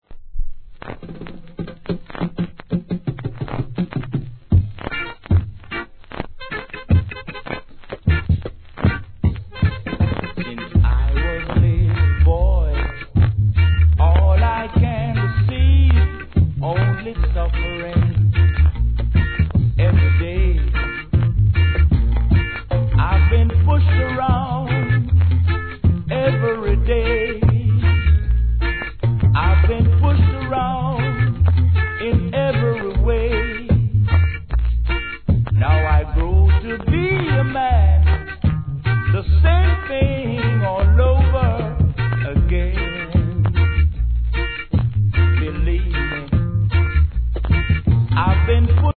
傷のため序盤に周期的なプツ入ります
REGGAE